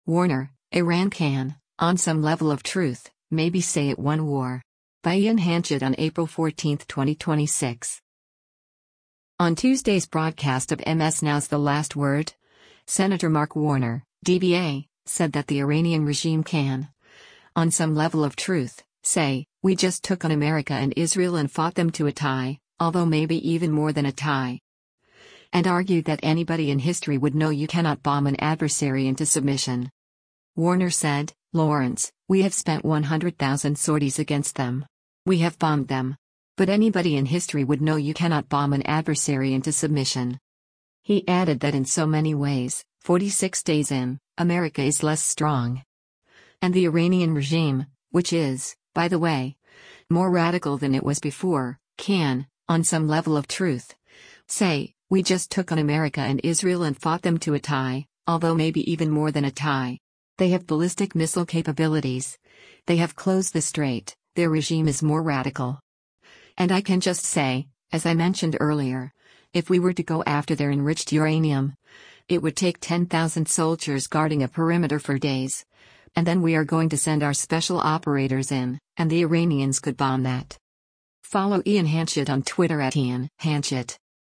On Tuesday’s broadcast of MS NOW’s “The Last Word,” Sen. Mark Warner (D-VA) said that the Iranian regime “can, on some level of truth, say, we just took on America and Israel and fought them to a tie, although maybe even more than a tie.” And argued that “anybody in history would know you cannot bomb an adversary into submission.”